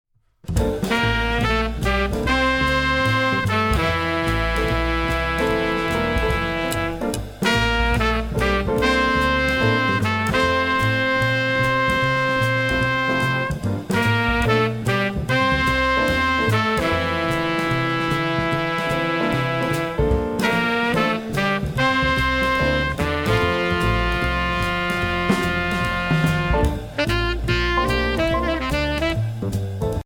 Voicing: Treble C